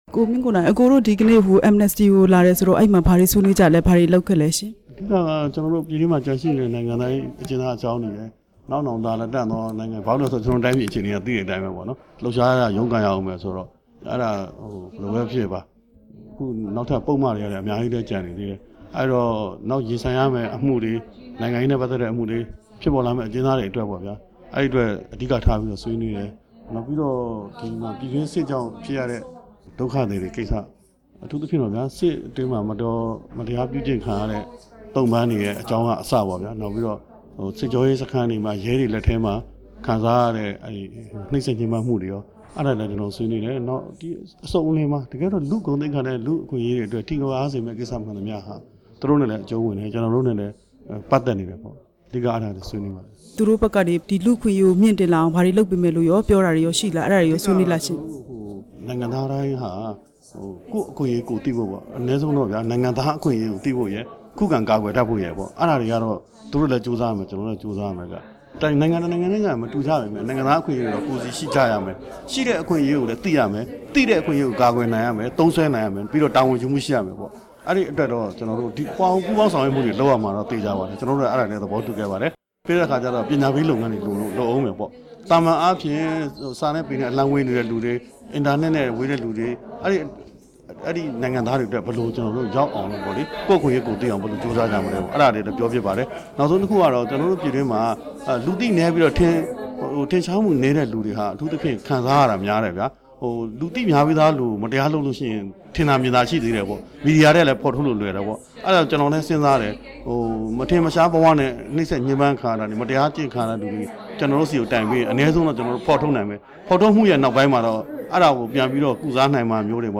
ကိုမင်းကိုနိုင်နဲ့ မေးမြန်းချက်